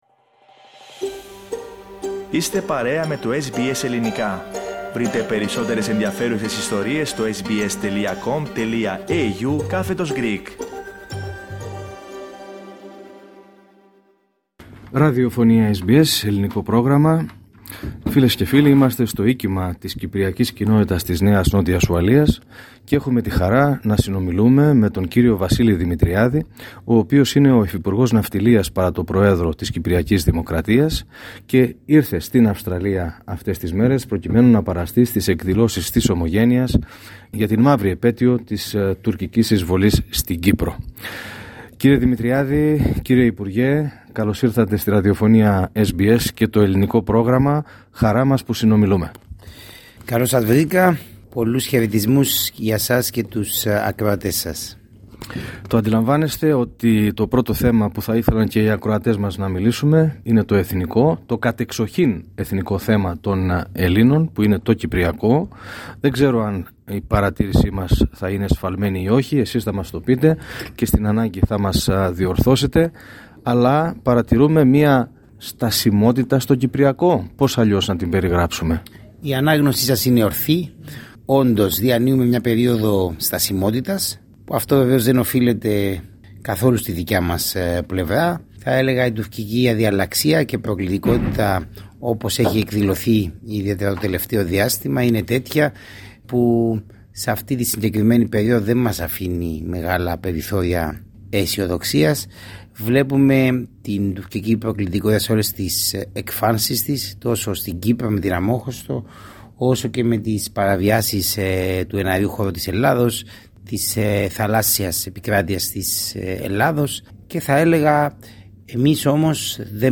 Στην συνέντευξη που παραχώρησε στο Πρόγραμμά μας, SBS Greek, ο κ. Δημητριάδης αναφέρθηκε πρώτα στο Κυπριακό αποδίδοντας την υφιστάμενη στασιμότητα στην άλλη πλευρά.